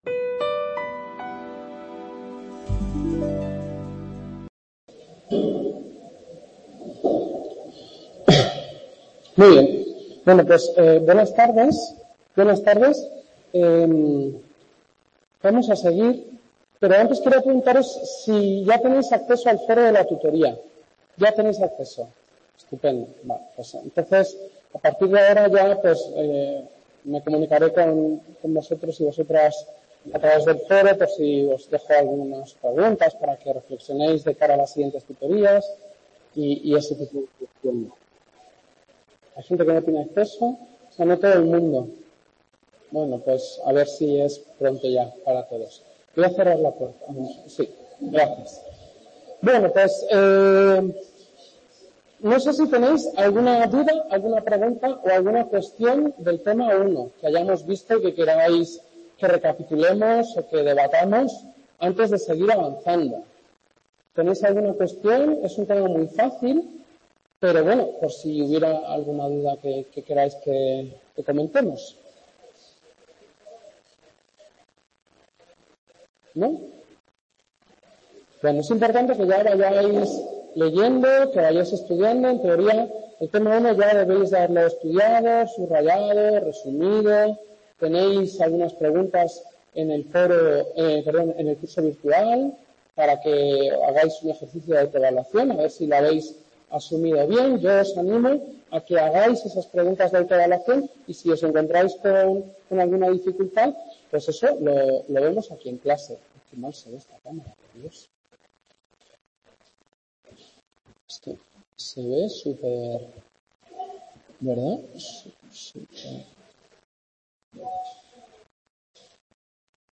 Primera clase T2: Funciones del sueño, tipos de sueño, procesos del sueño.